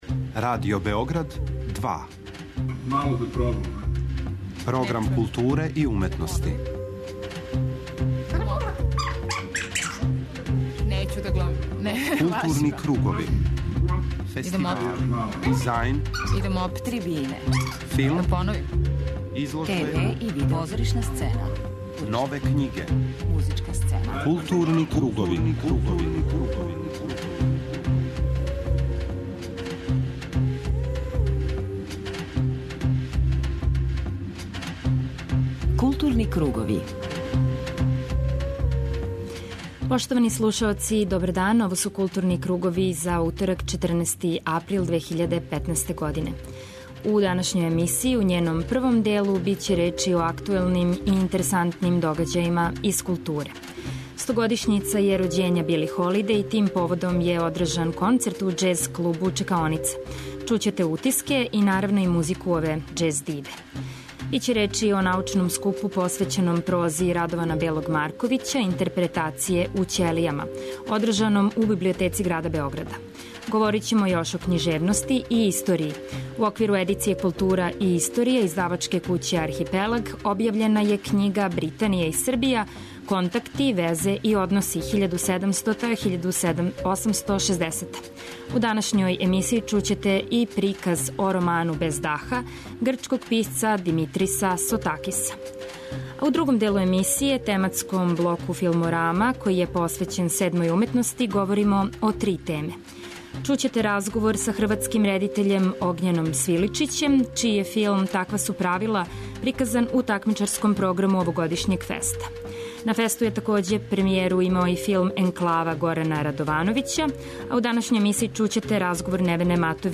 интервју